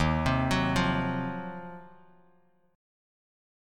D#m6add9 chord